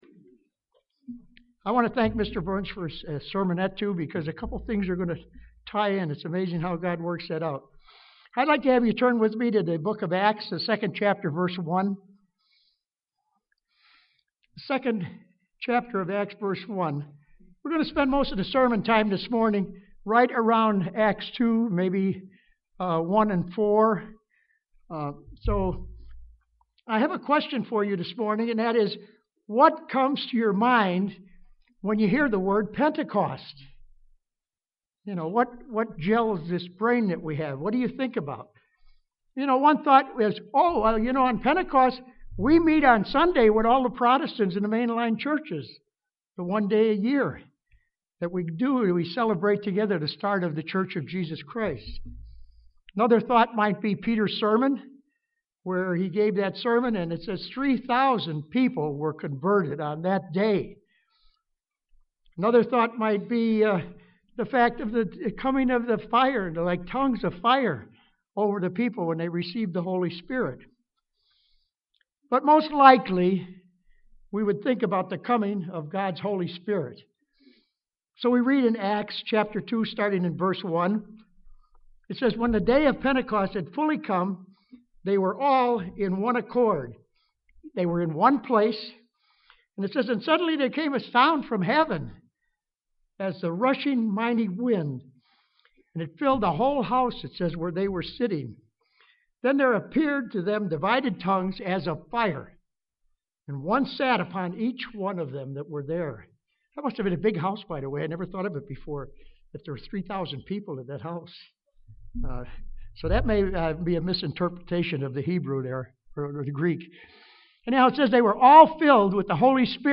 The true Pentecostal church is the one which reflects the marks of the church which started that day in 31AD, which is much different than the traditional Pentecostal churches of today. This message was given on the Feast of Pentecost.
UCG Sermon Studying the bible?